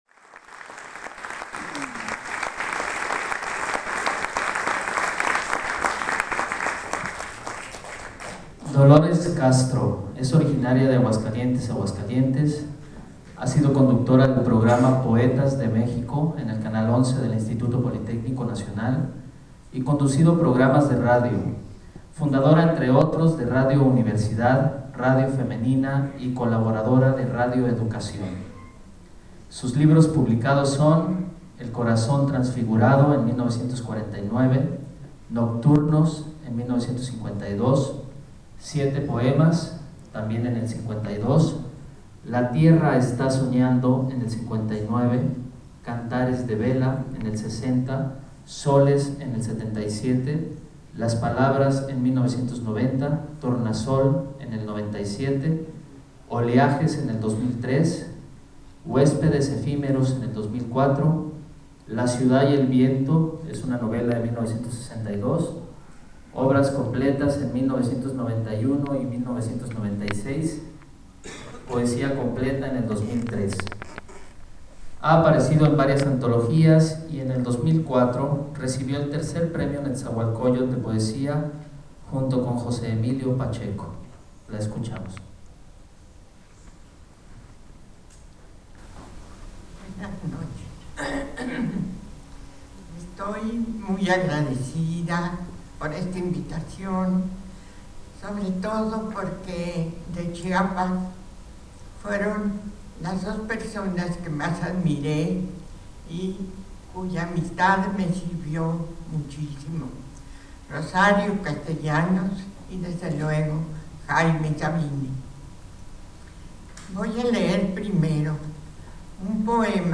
En la mesa de lectura del 2o. Festival Internacional de Letras Jaime Sabines 2008 se presentó la poeta Dolores Castro:
Lugar: Auditorio del Centro Cultural de Chiapas Jaime Sabines, Tuxtla Gutierrez, Chiapas. Mexico Equipo: iPod 2Gb con iTalk Fecha: 2008-10-29 07:59:00 Regresar al índice principal | Acerca de Archivosonoro